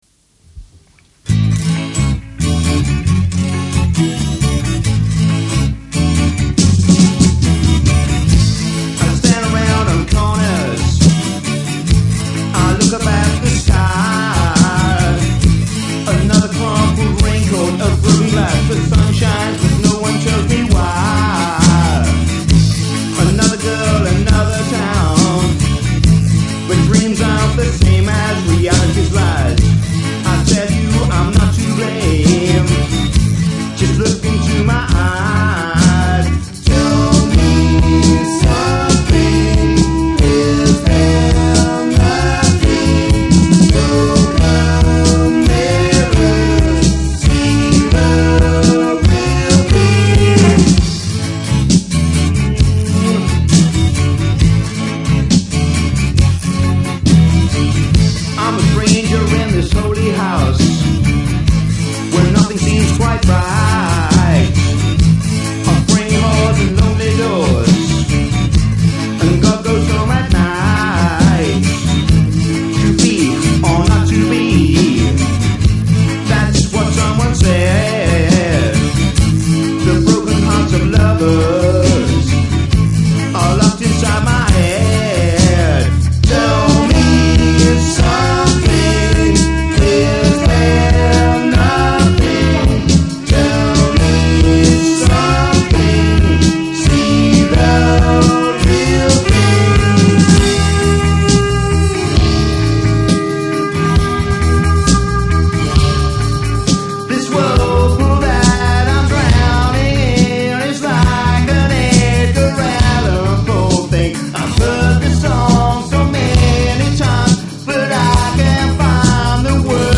Unfortunately, a lot of the stuff on this page will be at different volume levels, due to age  and what it was recorded on, but I'll try my best to keep it on a reasonable level.
Wilbury Grove circa 1982.
drums
bass, and I still can't remember who the sax player was.